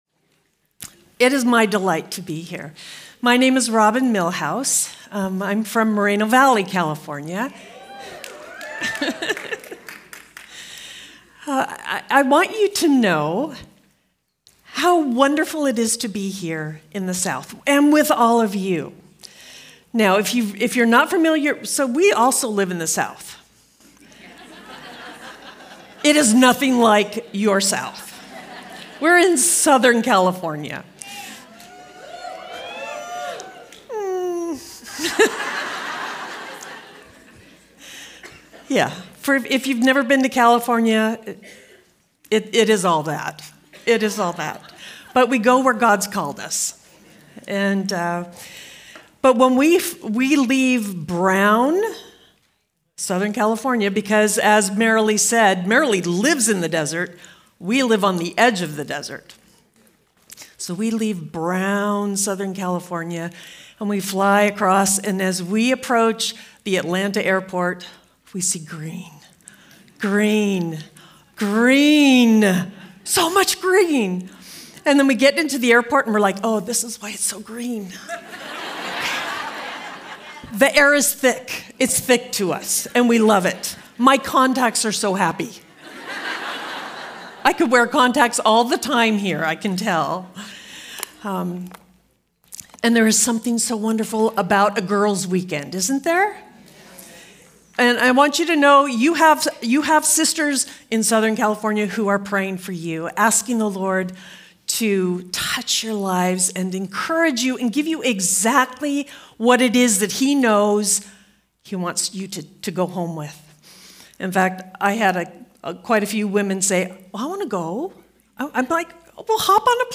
2026 DSWG Conference: Women's Gathering Date